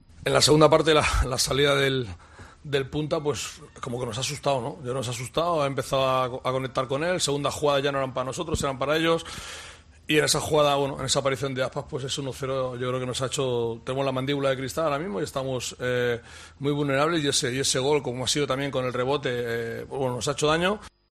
El entrenador del Cádiz ha analizado la dura derrota del equipo andaluz contra el Celta de Vigo.